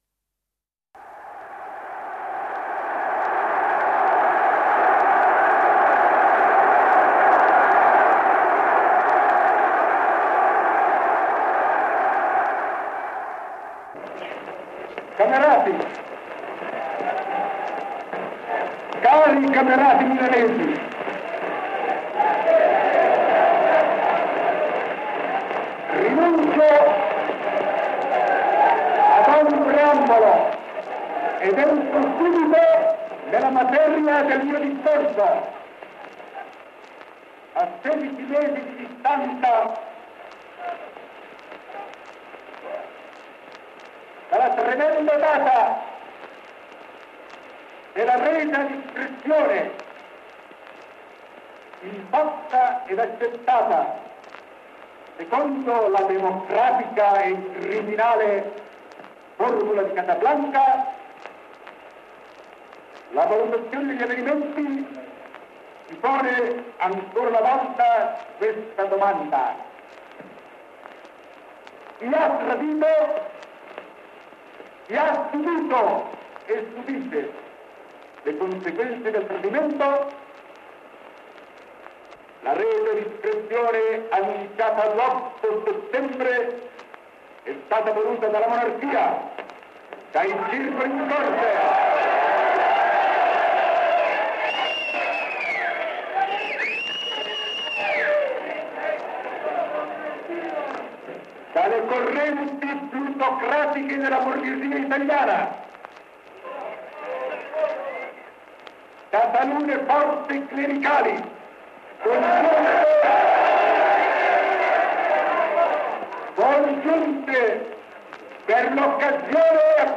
La ‘MEMORIA’ per l’appunto, riporta il vostro cronista al sedici dicembre millenovecentoquarantaquattro quando, nel Teatro Lirico di una paziente, ma distrutta Milano, è andato in scena